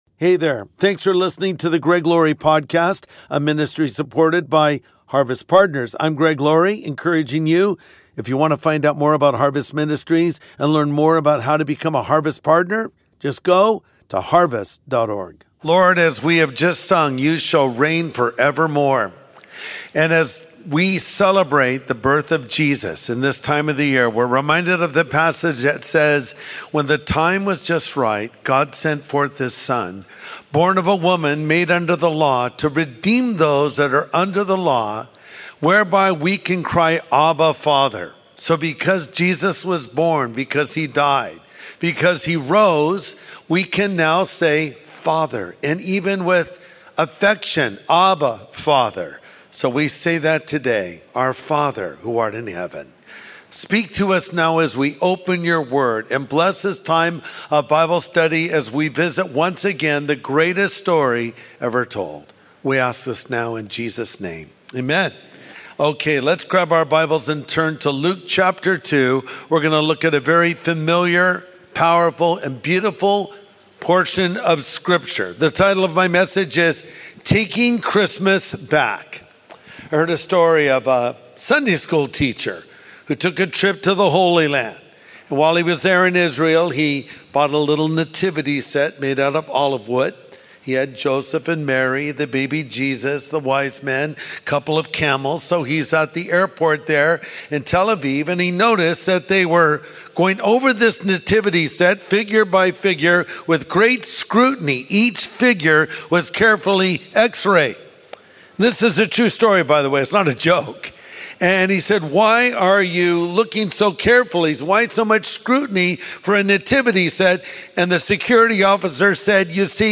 Taking Christmas Back | Sunday Message